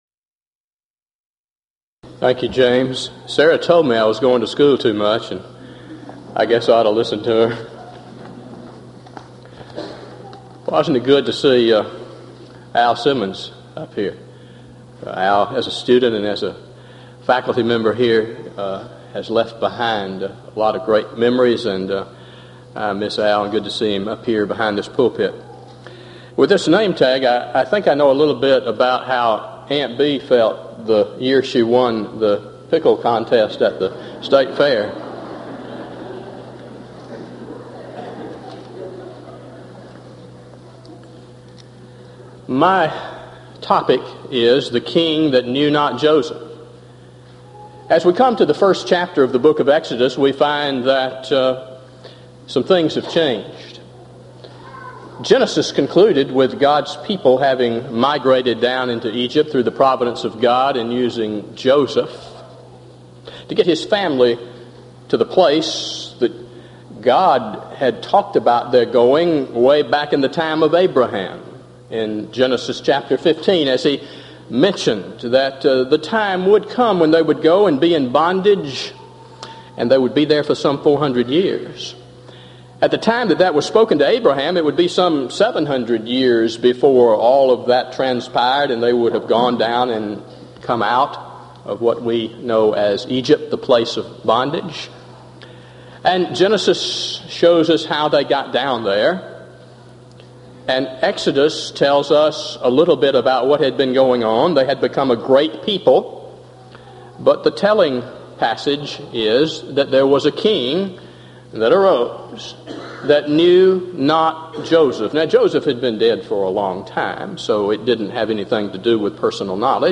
Event: 1997 East Tennessee School of Preaching Lectures Theme/Title: Studies In The Book of Exodus
lecture